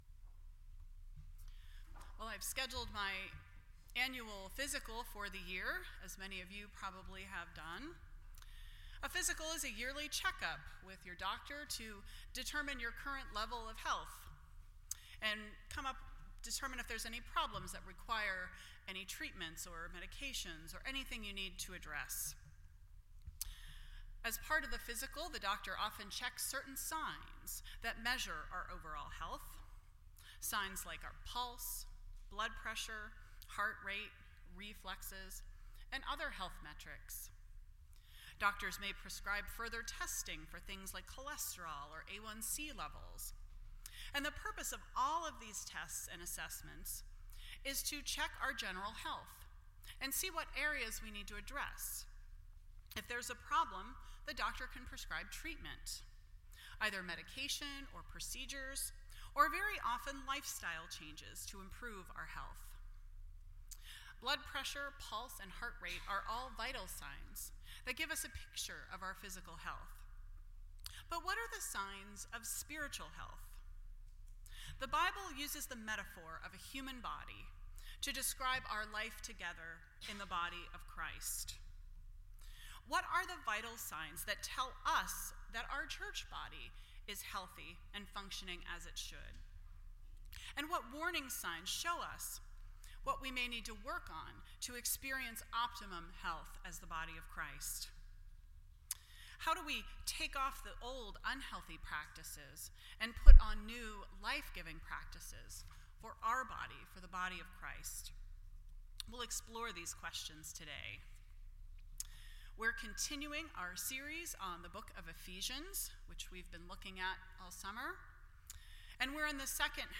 Series: Ephesians Service Type: Sunday Morning %todo_render% Share This Story